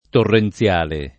[ torren ZL# le ]